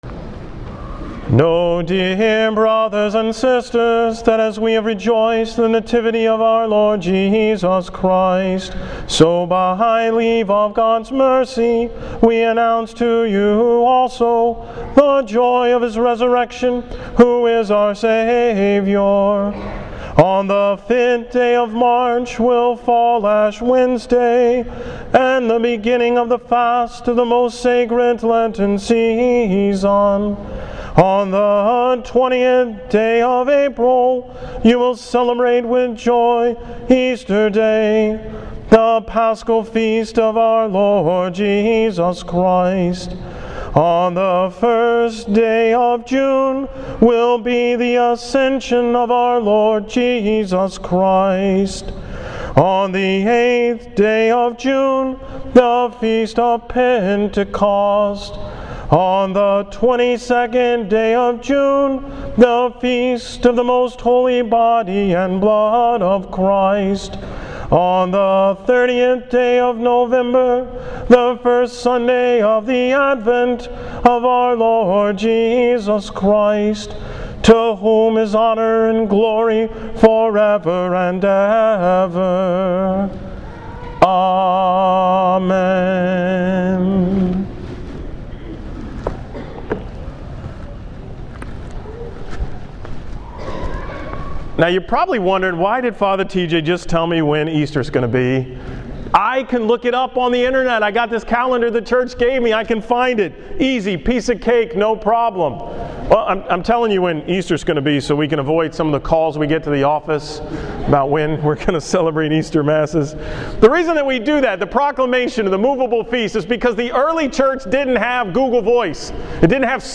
From the 9 am Mass on Sunday, January 5th